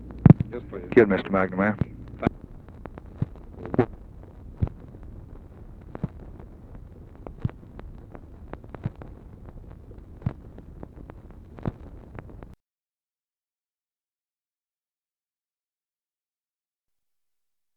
Conversation with (possibly) SIGNAL CORPS OPERATOR, January 2, 1964
Secret White House Tapes